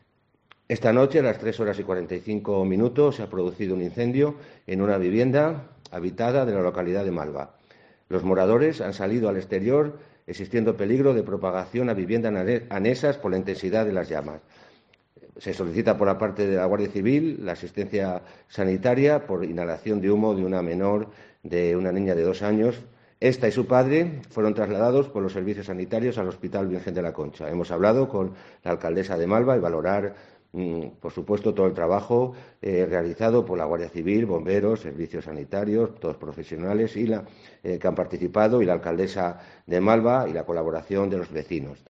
El subdelegado del Gobierno explicaba los hechos y mostraba su agradecimiento al trabajo de Guardia Civil, Bomberos y Ayuntamiento de Malva para evitar que el incendio tuviese peores consecuencias.